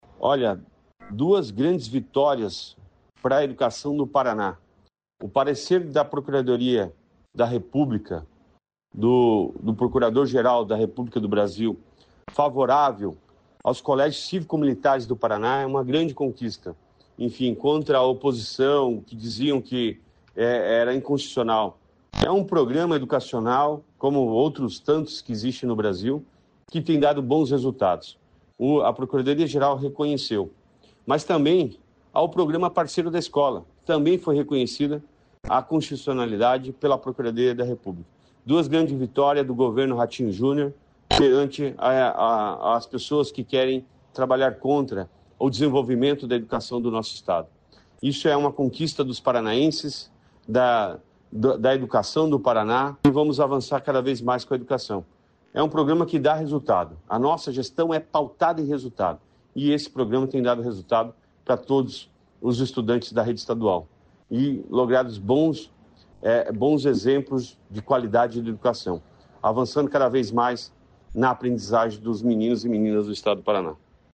Sonora do secretário da Educação, Roni Miranda, sobre legalidade do programa de escolas cívico-militares do Paraná